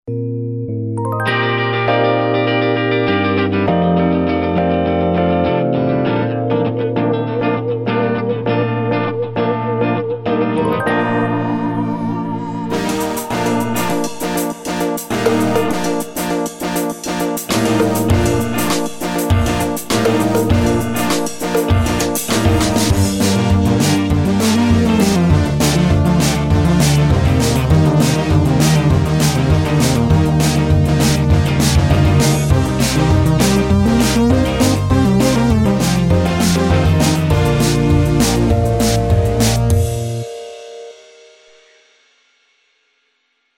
a boy / girl duet experimenting with samples and stuff